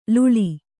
♪ luḷi